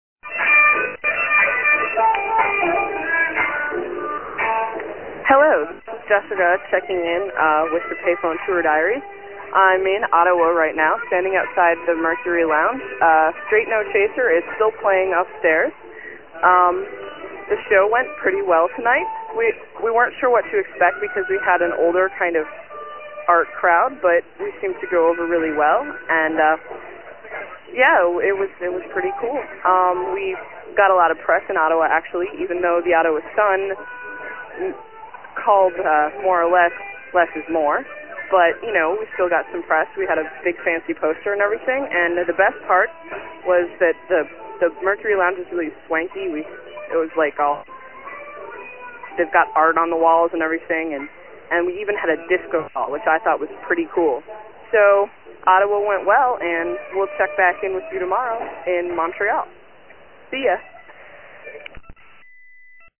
this one — came complete with mood setting music…